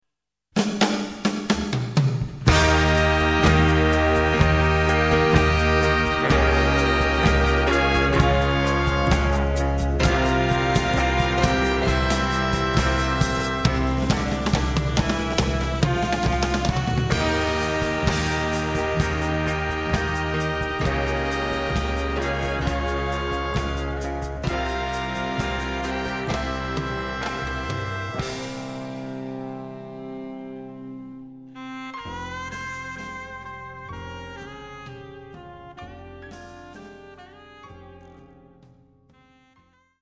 これらの名曲がラウンジ風味のインストゥルメンタル・ナンバーとして演奏されているのがなんとも涼しげ。